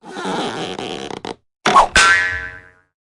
На этой странице собраны реалистичные звуки выстрелов из рогатки разными снарядами: от камней до металлических шариков.
Звук смешного выстрела из рогатки